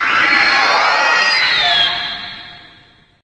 The roar of Mothra's imago form in the 2003 film Godzilla: Tokyo S.O.S.
Mothra_Roars_Tokyo_SOS.ogg